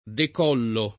decollo [ dek 0 llo ] s. m.